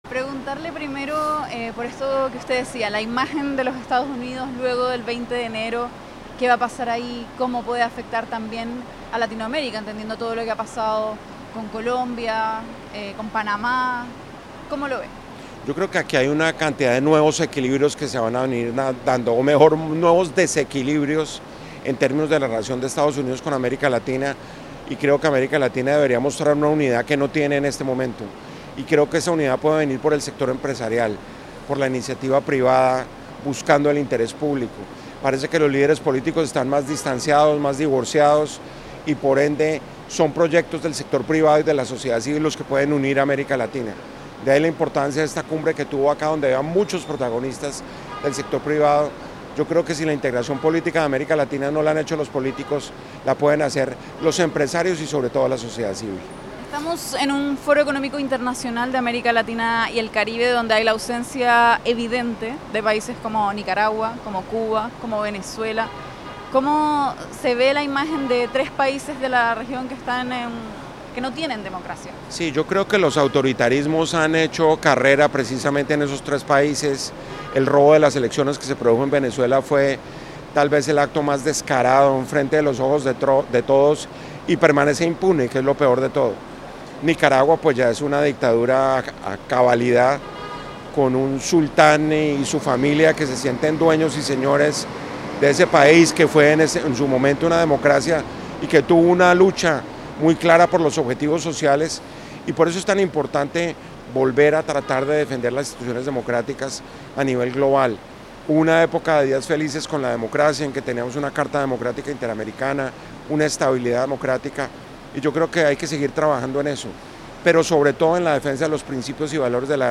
Entrevista a Fernando Carillo, Vicepresidente de Prisa Media